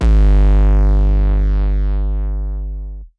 Metro 808s [Ugly].wav